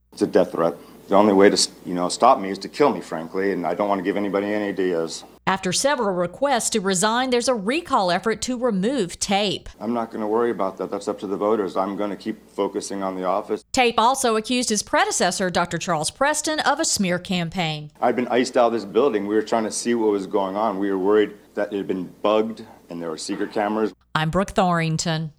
Dr. Christopher Tape, St. Tammany Parish Coroner speaks to the media after a suspicious was found at his office. March 25, 2024.
At a press conference Monday, he talked about his first day as coroner.